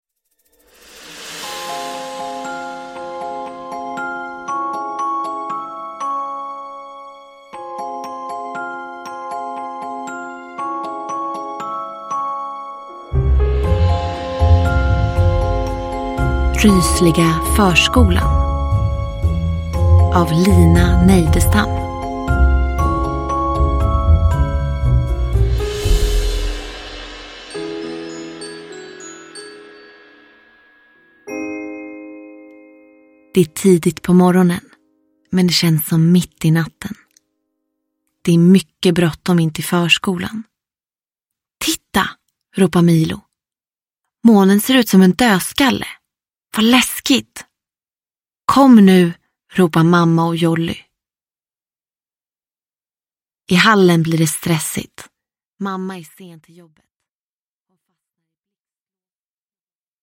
Rysliga förskolan – Ljudbok – Laddas ner
Perfekt högläsning för alla spänningsälskande förskolebarn.